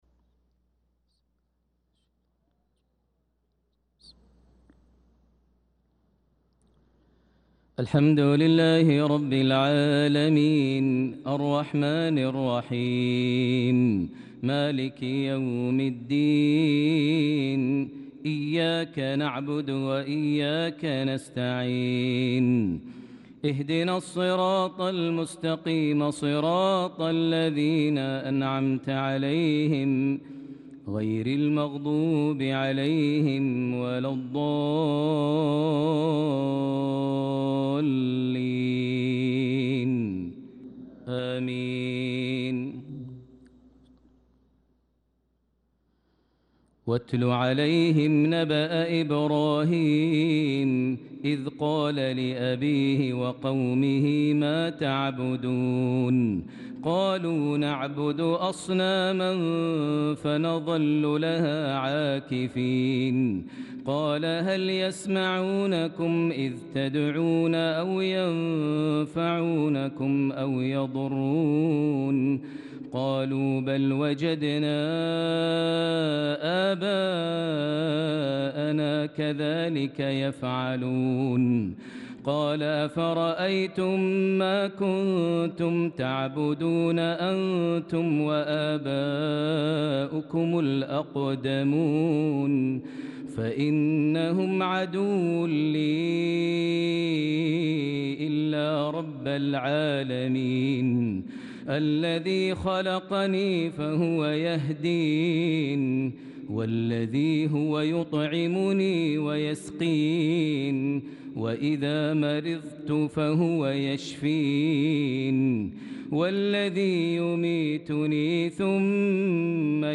صلاة العشاء للقارئ ماهر المعيقلي 21 ذو القعدة 1445 هـ
تِلَاوَات الْحَرَمَيْن .